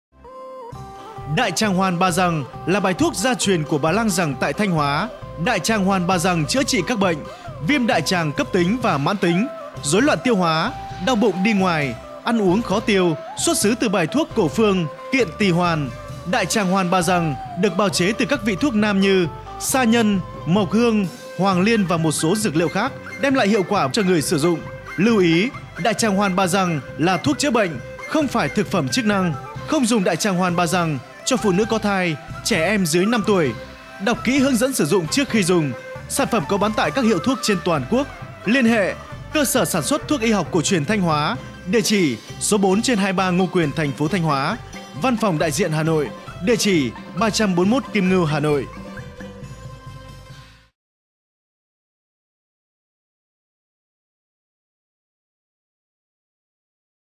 Trên đài phát thanh cho công chúng